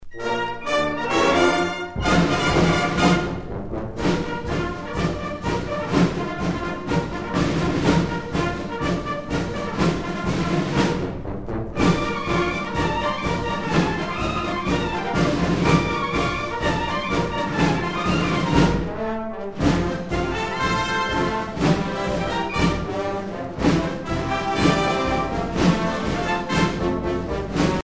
Fonction d'après l'analyste gestuel : à marcher
Usage d'après l'analyste circonstance : militaire